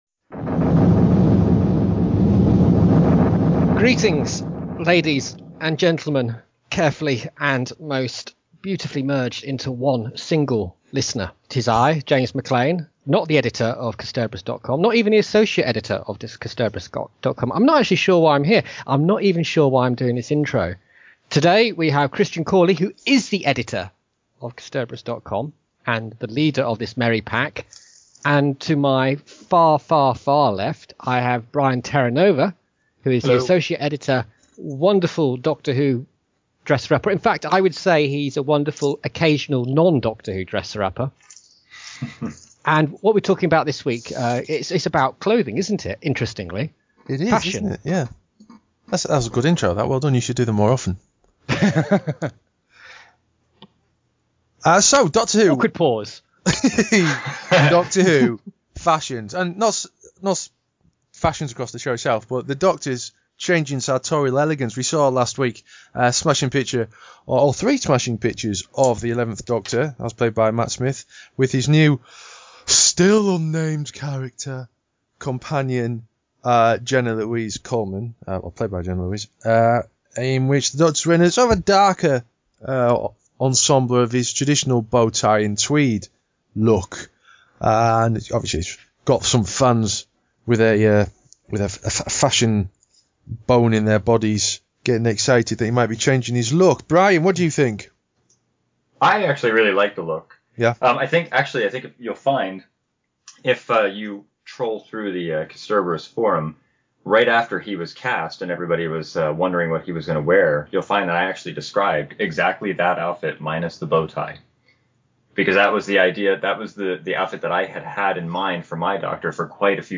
have a right old chat about the situation in the latest podKast, concluding with a declaration of their individual favourite looks across Doctor Who's history.